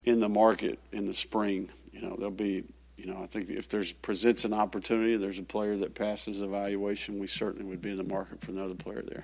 Napier said the team plans to take advantage of any opportunities that present themselves, especially at the quarterback position to complete the 2023 recruiting class.